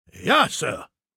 Datei:Maleold01 ms06 goodbye 000bc2e8.ogg
Fallout 3: Audiodialoge